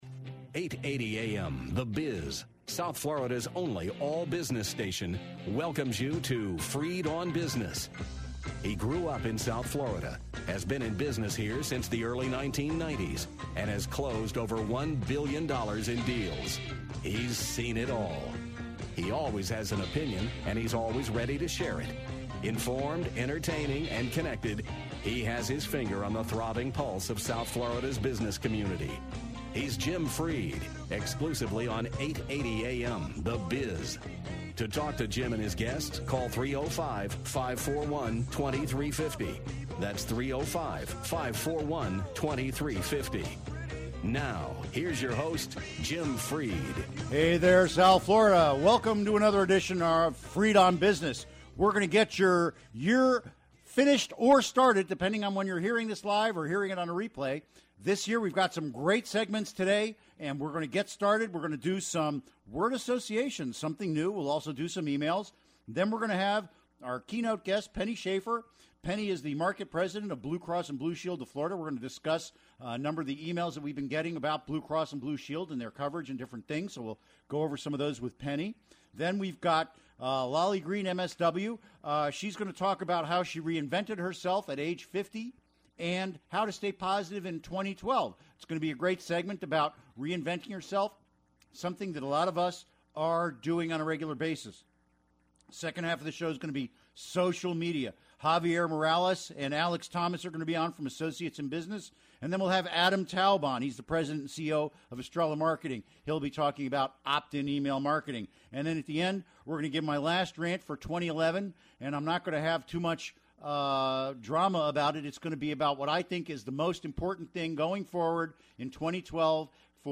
Listener E mails and Business Talk